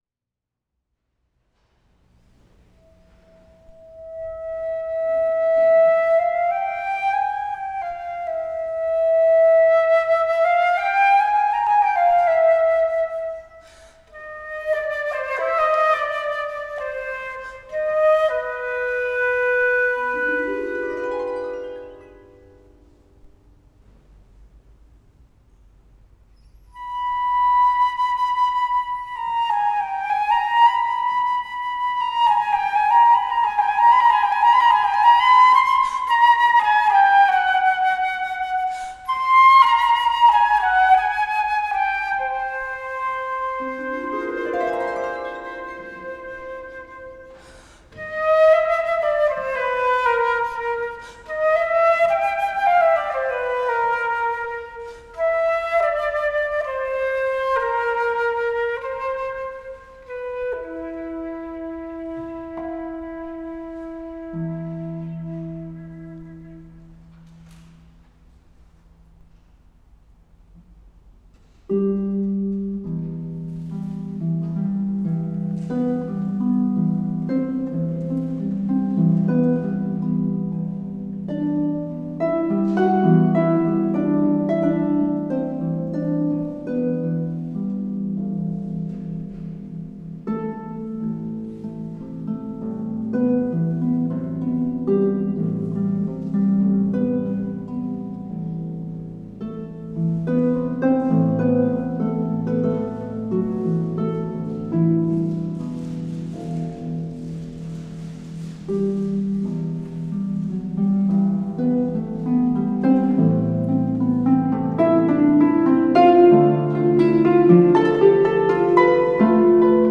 dwarsfluit
harp